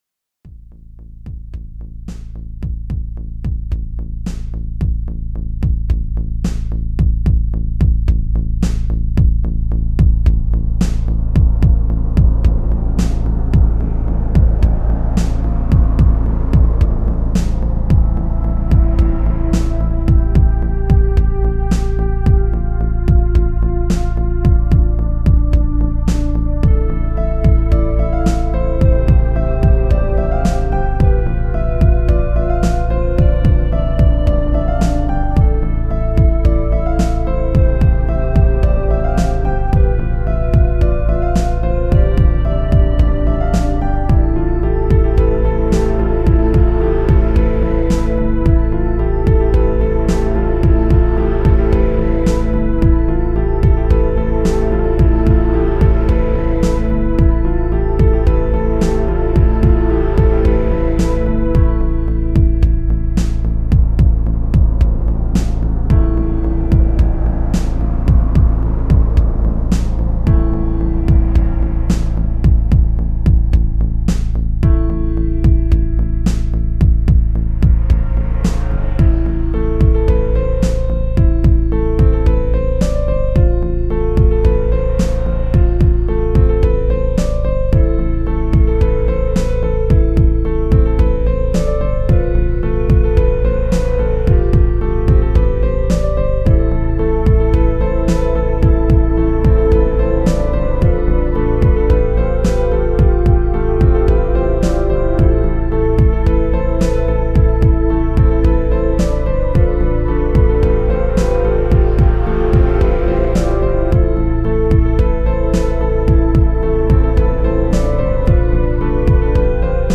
Ambo Rock